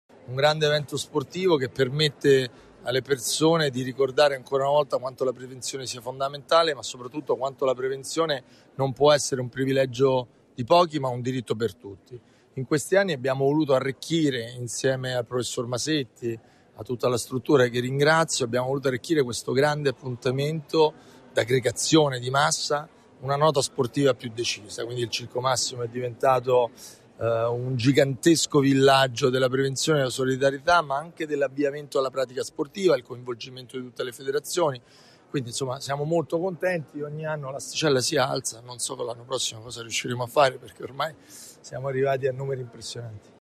Le Voci dell’Evento
L’Assessore Onorato: “Un evento fondamentale per la città di Roma…”